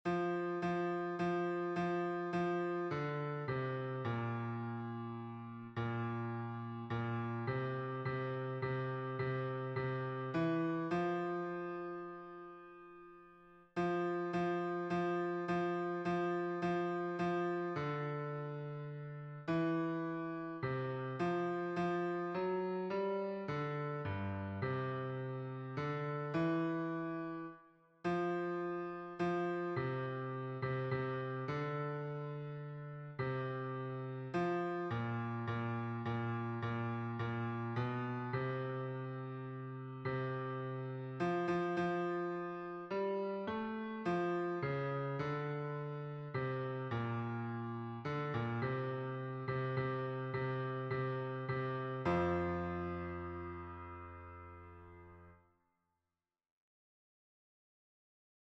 #3034: Turn Your Eyes upon Jesus — Bass Audio | Mobile Hymns